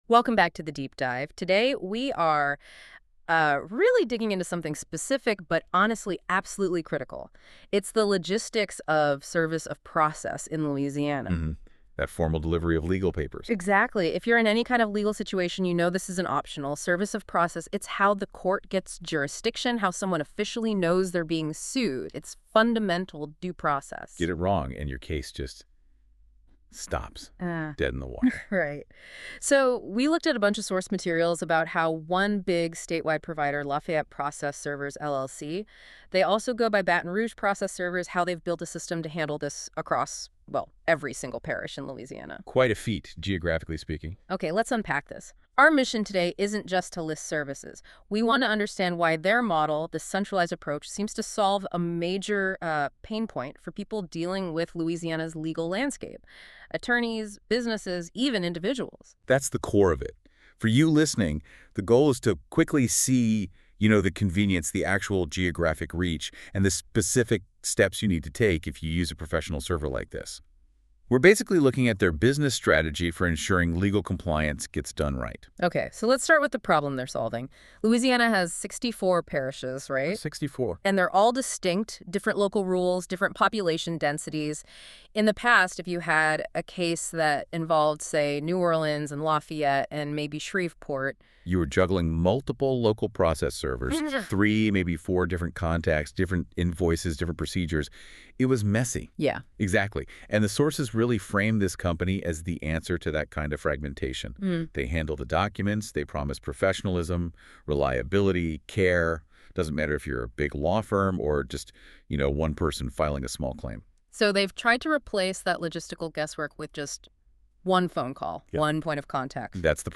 This is an AI generated episode.